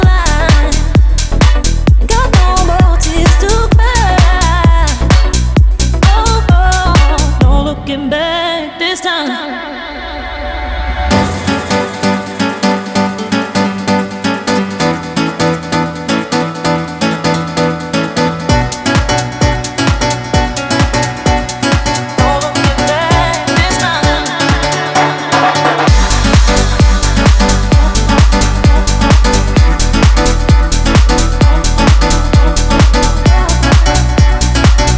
Жанр: Танцевальные